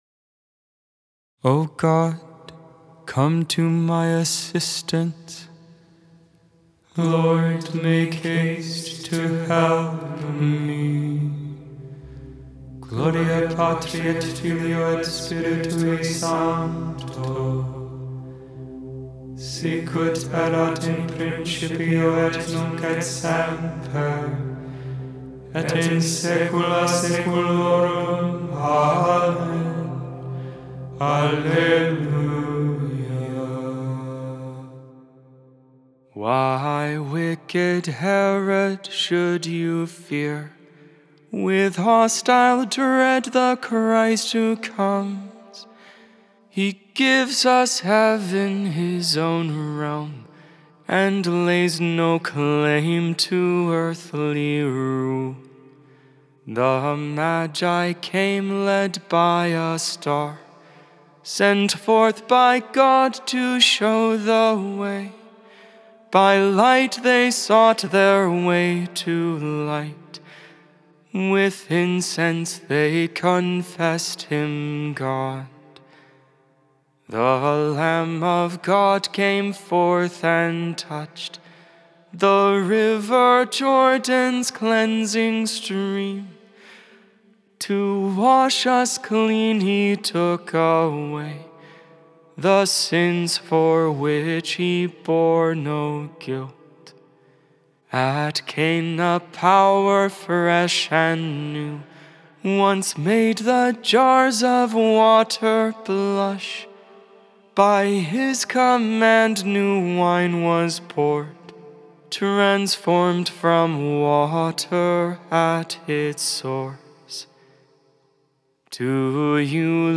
1.10.25 Vespers, Friday Evening Prayer of the Liturgy of the Hours